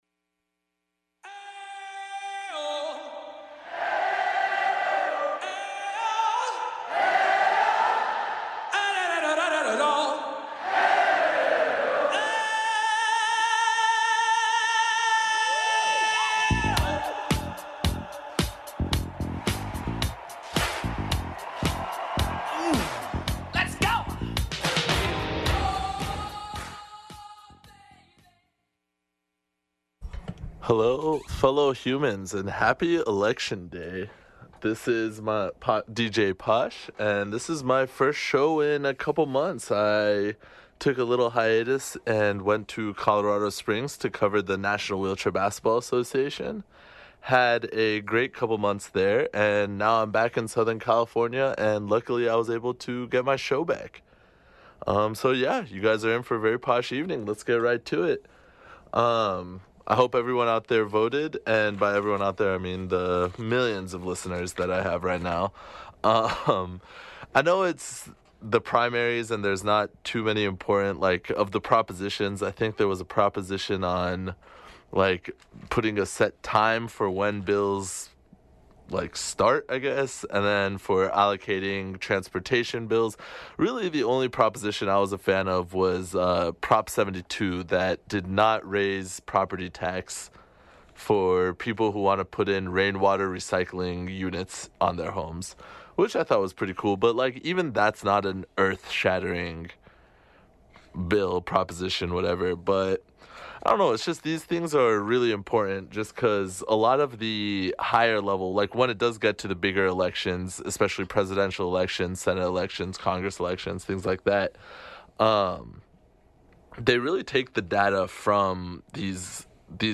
*This is a recording of a live show from the non-profit station, KXSC.